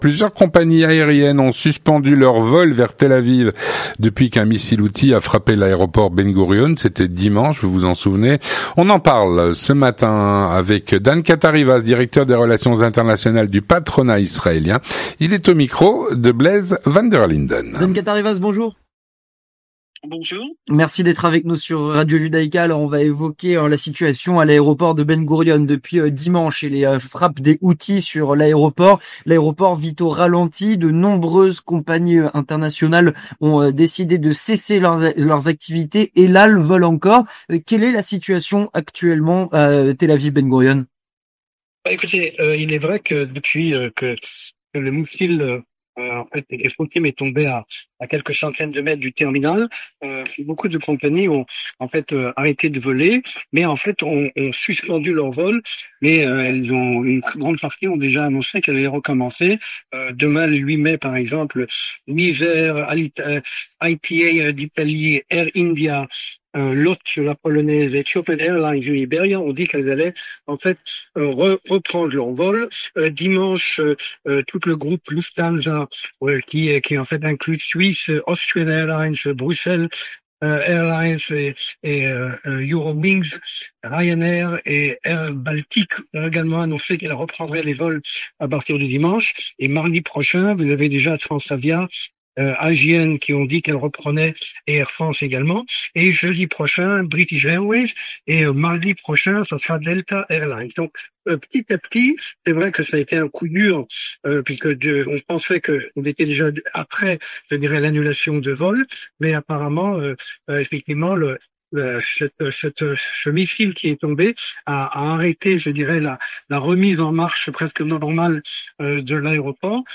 L'entretien du 18H - Plusieurs compagnies aériennes ont suspendu leurs vols vers Tel-Aviv.